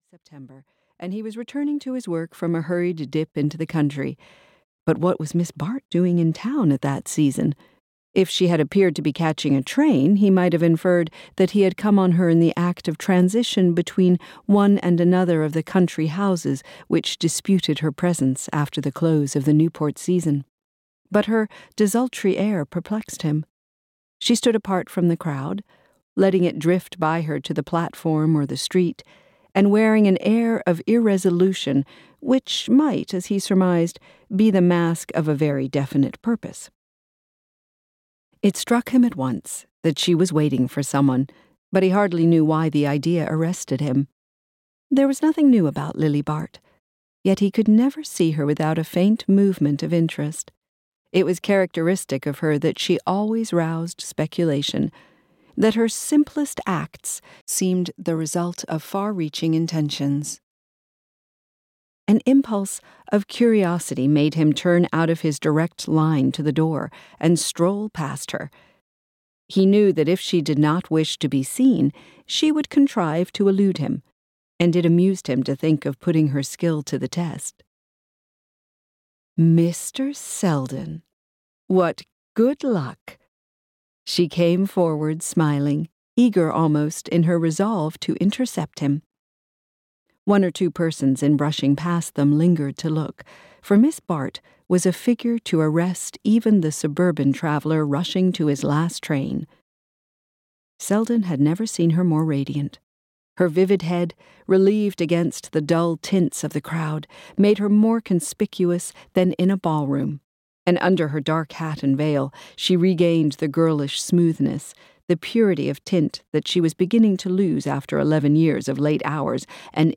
The House of Mirth (EN) audiokniha
Ukázka z knihy